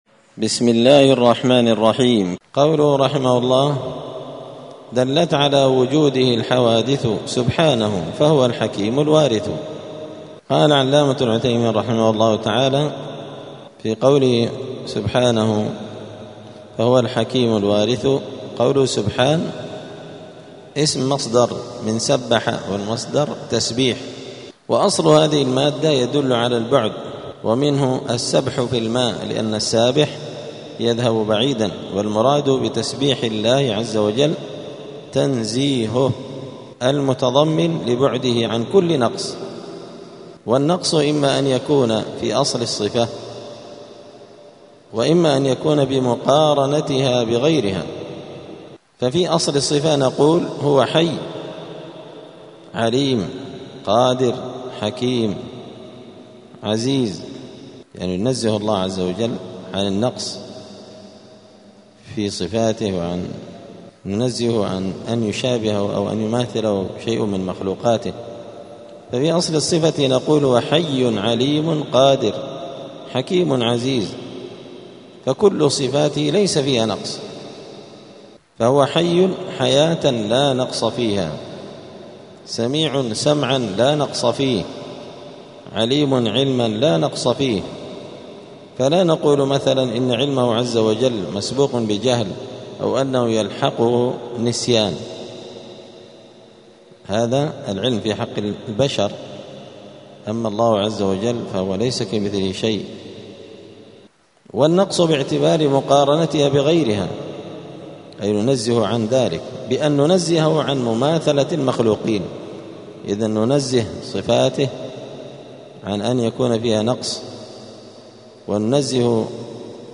دار الحديث السلفية بمسجد الفرقان قشن المهرة اليمن
10الدرس-العاشر-من-شرح-العقيدة-السفارينية.mp3